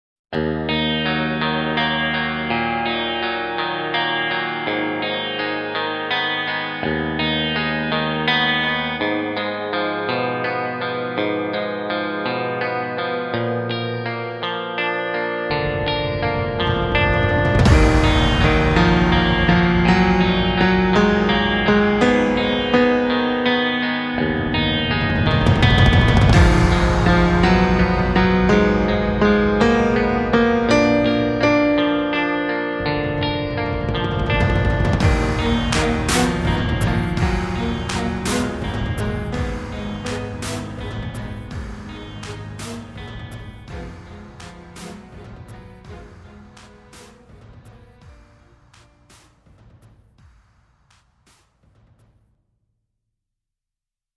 Guitars, keyboards, vocals
Lead Vocals, guitars
Drums, vocals